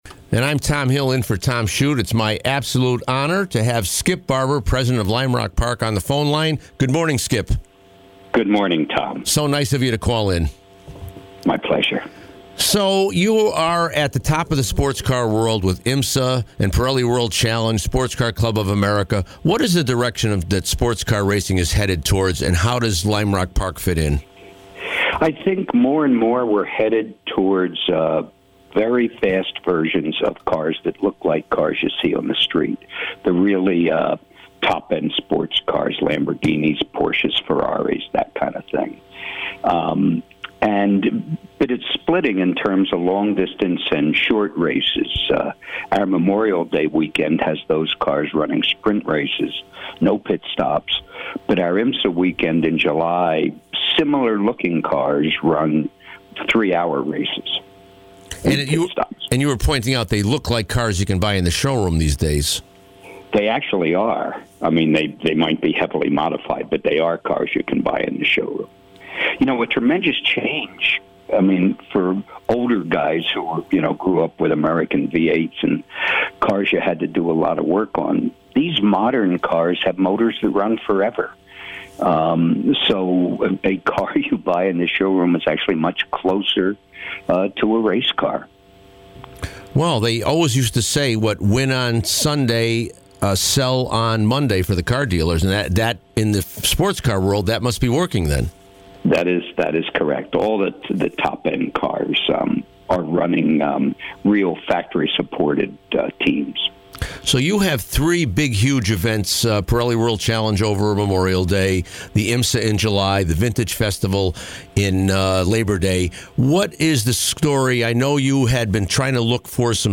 WATR morning show